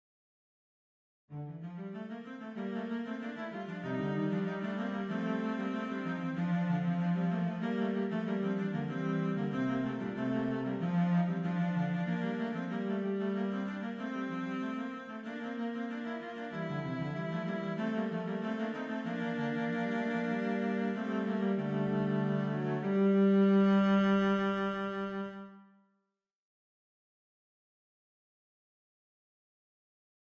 Pour des raisons de clarté auditive, les exemples audios seront ici donnés avec des sons de violoncelle, ceux ci étant préférables aux sons de voix synthétiques.
Les entrées sont toujours à la quarte.
A la fin, on trouve une vocalise jubilatoire sur le mot « gloria ».